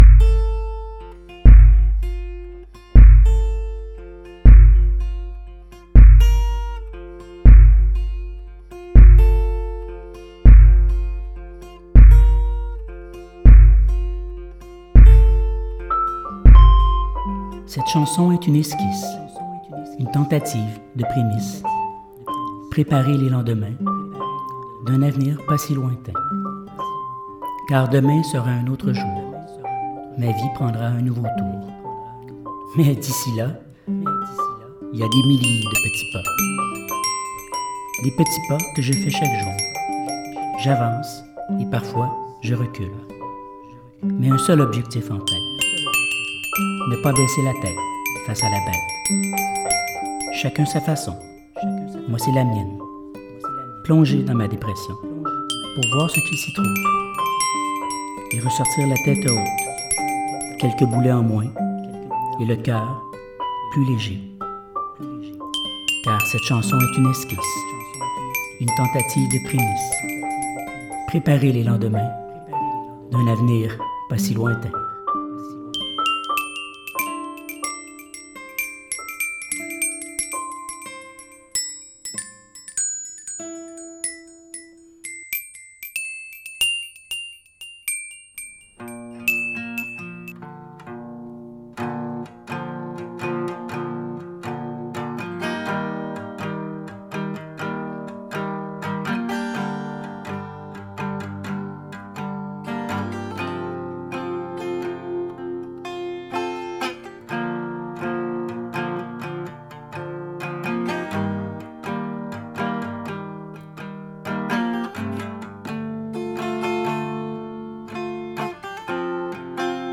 Ici, y a la version avec paroles :